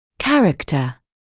[‘kærəktə][‘кэрэктэ]характер; качество, свойство